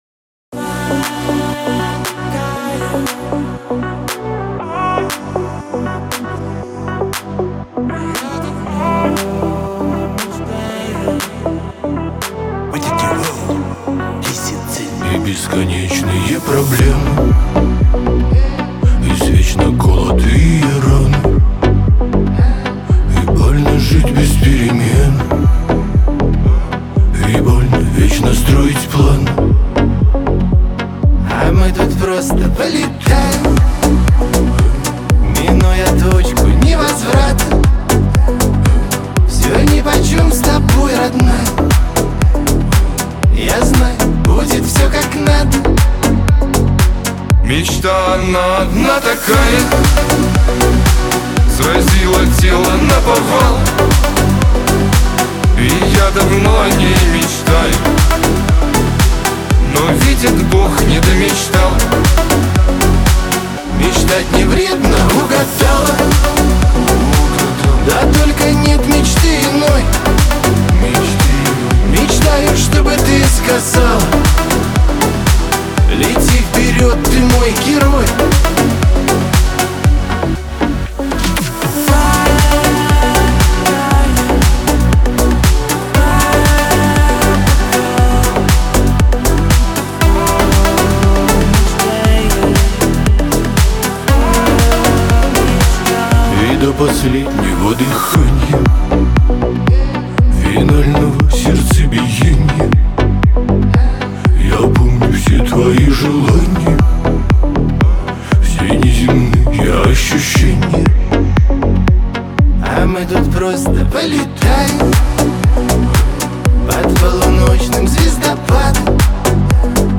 Жанр: shanson